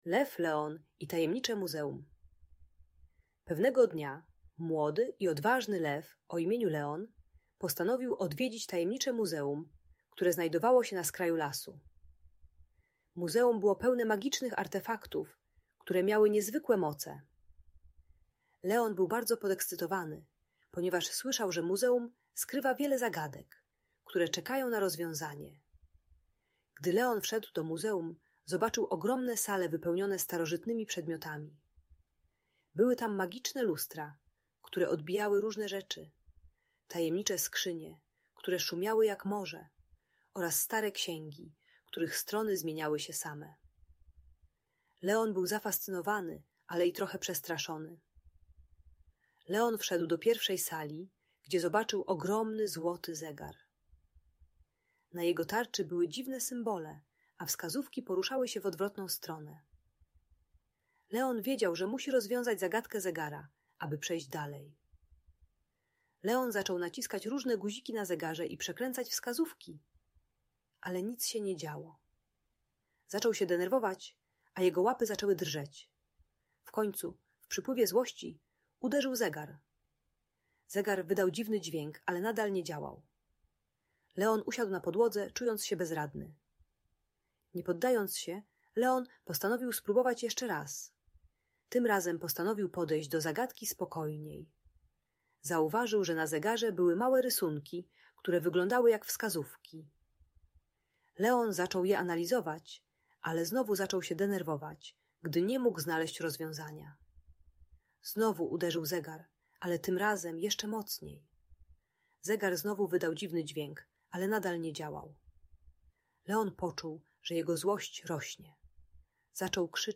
Lew Leon i Tajemnicze Muzeum - Agresja do rodziców | Audiobajka